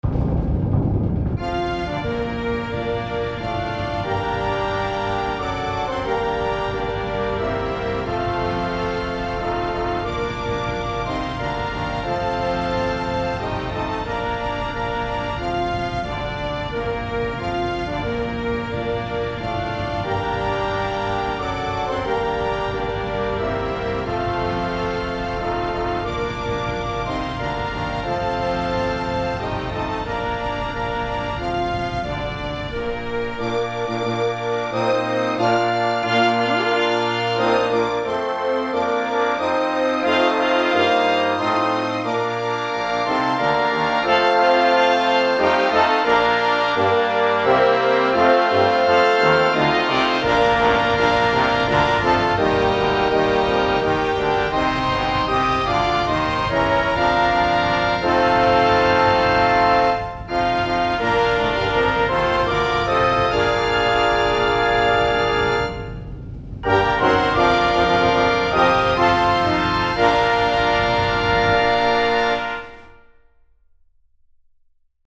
National Anthem (USA) full orchestra
I took my band arrangement, deleted the bass clr, alto sax, tenor sax, bari sax, and baritone and put in the vn 1, vn 2, viola, cello double bass and that equals a full orchestra with the exception of the timp.